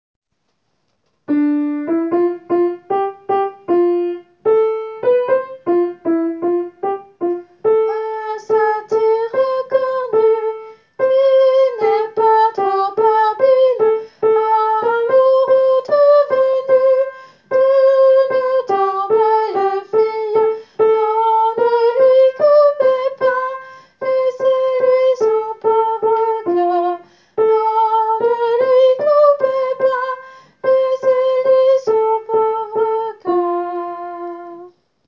Tenor :
satire-tenor.wav